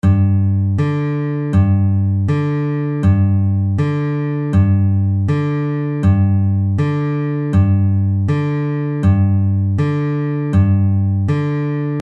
Exercise 3 - Alternate Bass
This entire exercise is played with the thumb.
Alternate Bass in G